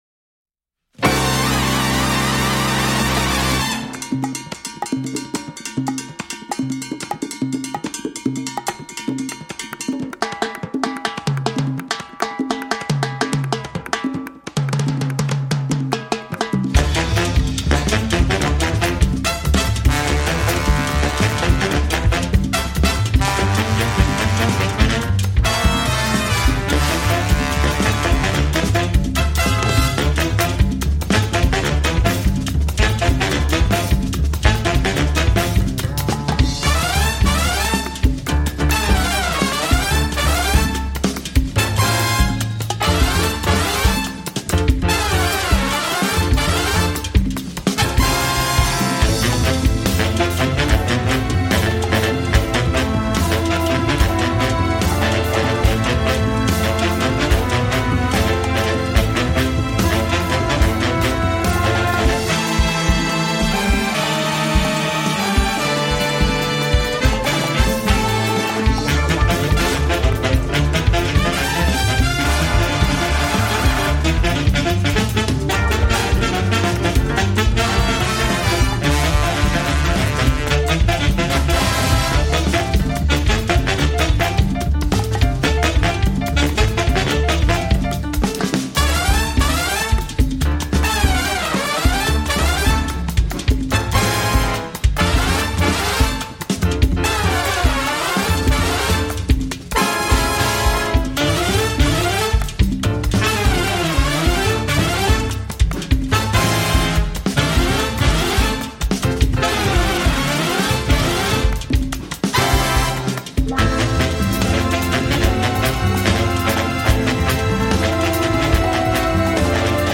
avec son jazz, ses guitares et percussions latinos